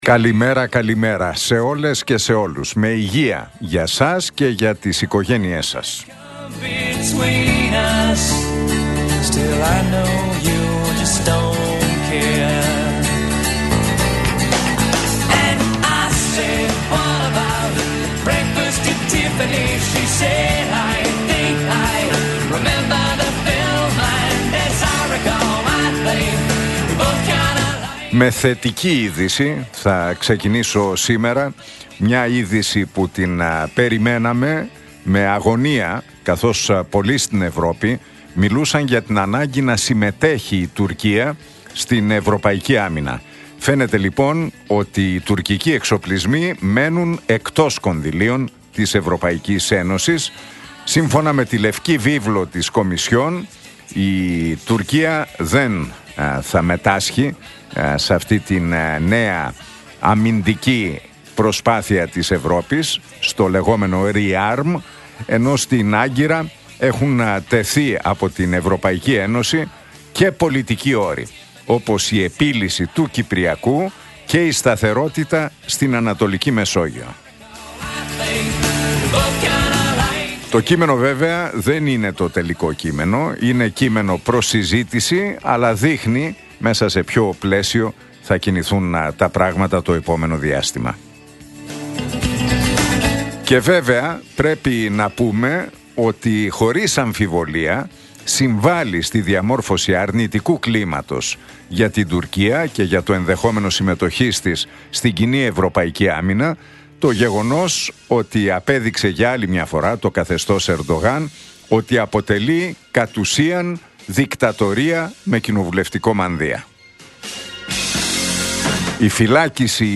Ακούστε το σχόλιο του Νίκου Χατζηνικολάου στον ραδιοφωνικό σταθμό RealFm 97,8, την Πέμπτη 20 Μαρτίου 2025.